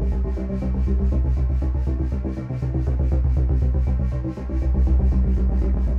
Index of /musicradar/dystopian-drone-samples/Tempo Loops/120bpm
DD_TempoDroneE_120-C.wav